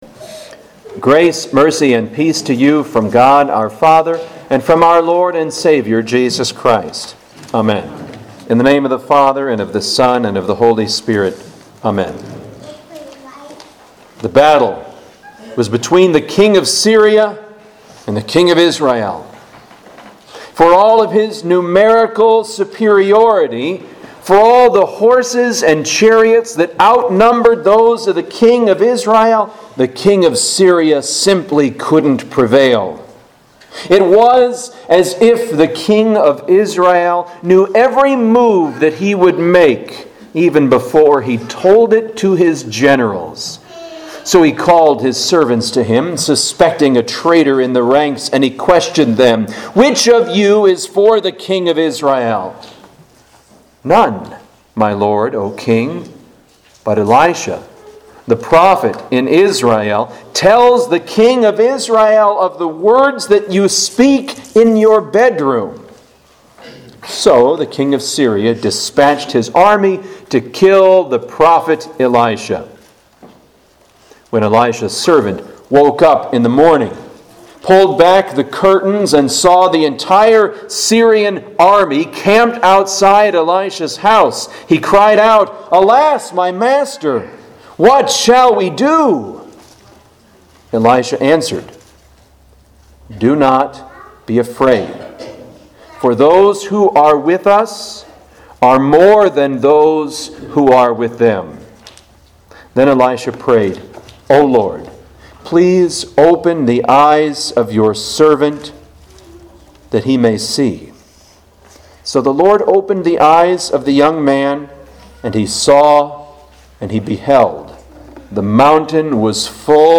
Home › Sermons › St. Michael & All Angels